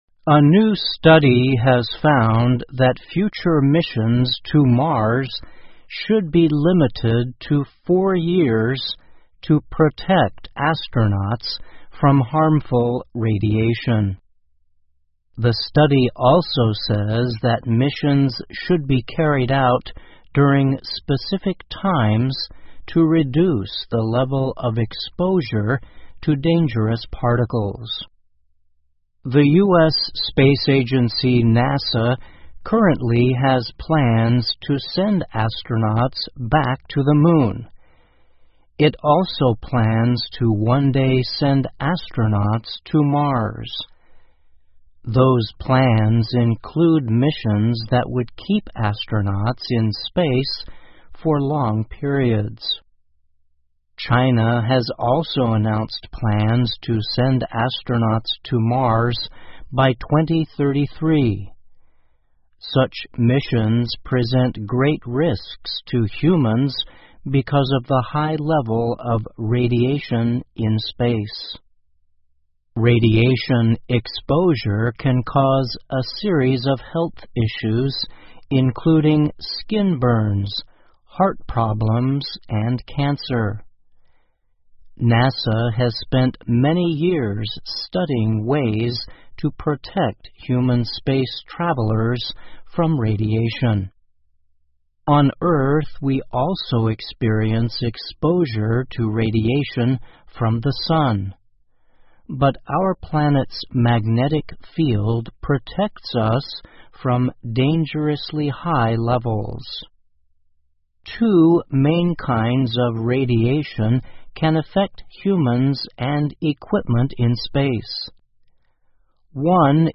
VOA慢速英语2021--辐射研究发现宇航员火星任务时间不能超过4年 听力文件下载—在线英语听力室